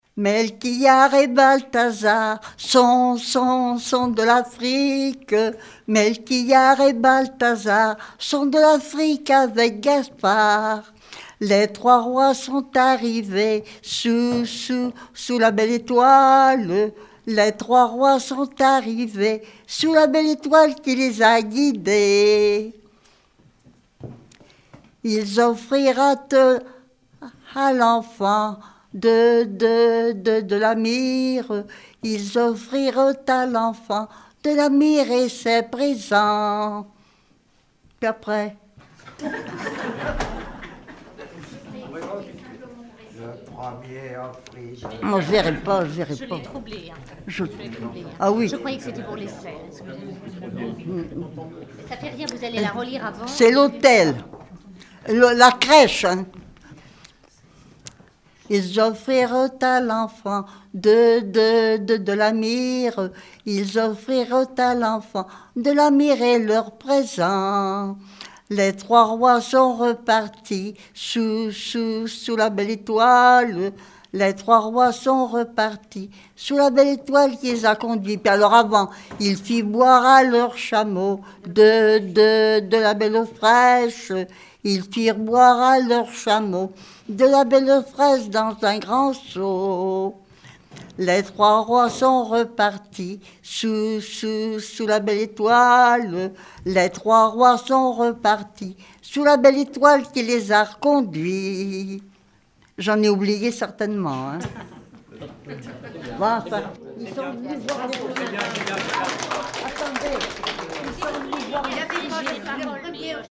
Mémoires et Patrimoines vivants - RaddO est une base de données d'archives iconographiques et sonores.
Genre strophique
Regroupement de chanteurs du canton
Pièce musicale inédite